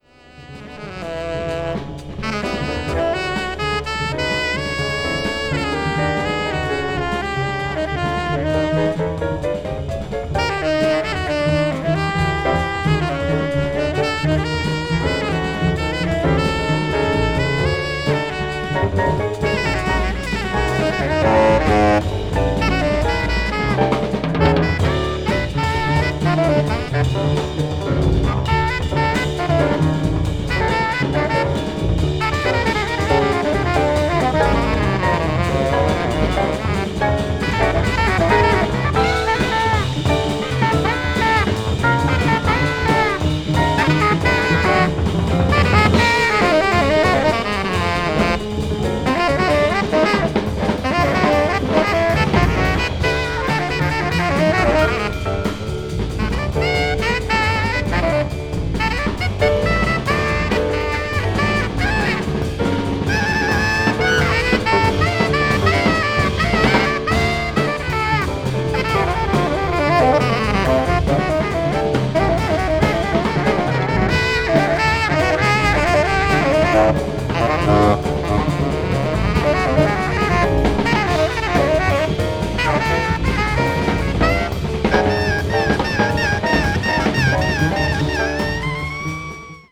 media : EX/EX(わずかにチリノイズが入る箇所あり)
北欧民族音楽的な旋律が聴けるイントロから開放感溢れるスピリチュアル・ジャズへと展開するB1
avant-jazz   contemporary jazz   post bop   spiritual jazz